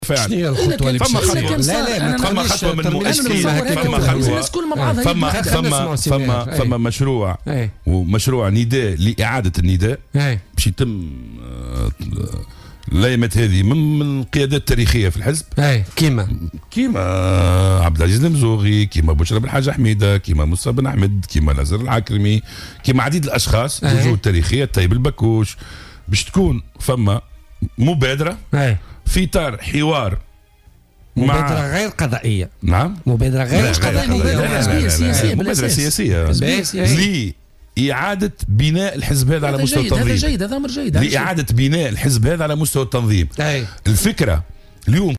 وأوضح ضيف "بوليتيكا" أن التحركات ستبدأ خلال أيام من طرف قيادات تاريخية من الحزب مثل عبد العزيز المزوغي وبشرى بلحاج حميدة ومصطفى بن أحمد الطيب البكوش لزهر العكرمي وغيرها.